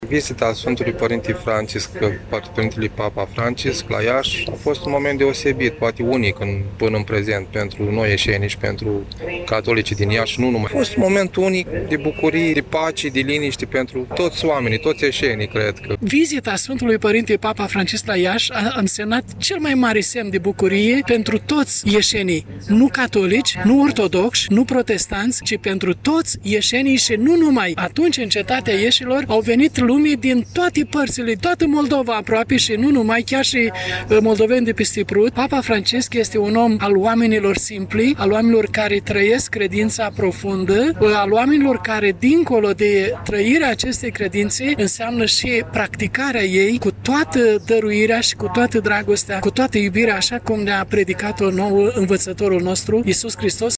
Persoanele prezente, astăzi, la Liturghia din cea de a treia zi de Paște, de la Catedrala Romano-Catolică, au rememorat vizita din 2019 a Papei Francisc la Iași: